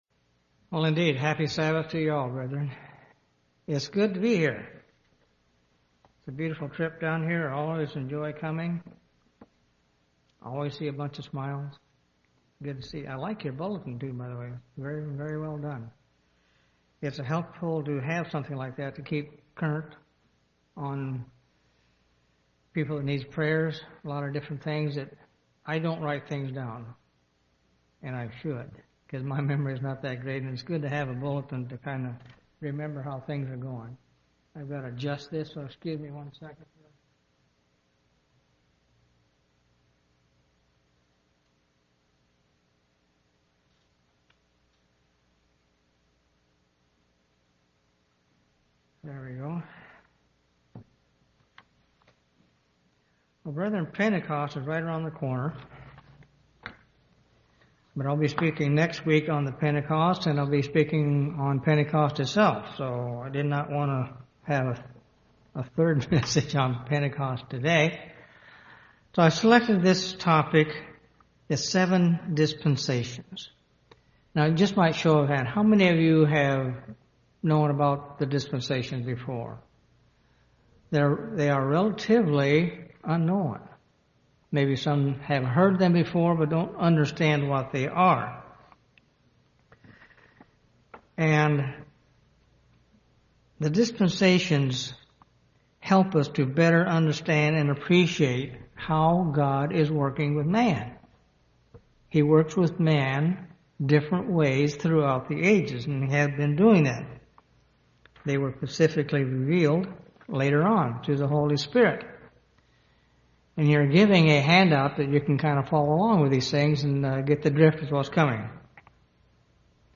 This sermon discusses seven dispensations God has used in dealing with humanity. These ages are: innocence, conscience, human government, promise, law, grace, and fullness of times.